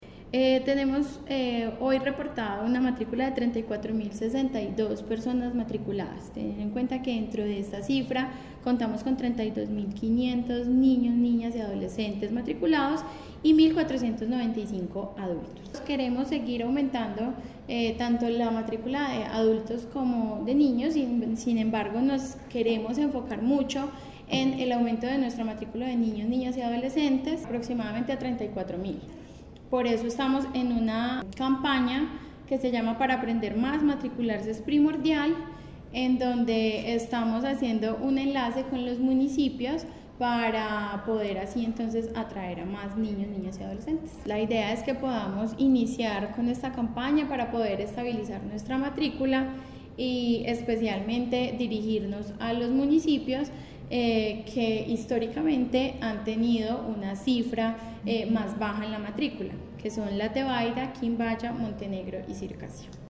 Audio de: Tatiana Hernández Mejía, secretaria de Educación, sobre campaña de incentivo a la matrícula
Tatiana-Hernandez-Mejia-secretaria-de-Educacion-sobre-campana-de-incentivo-a-la-matricula.mp3